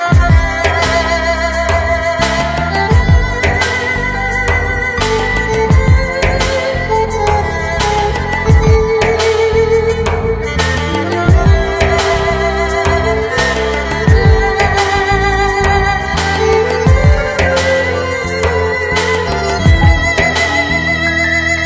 Category: Punjabi Ringtones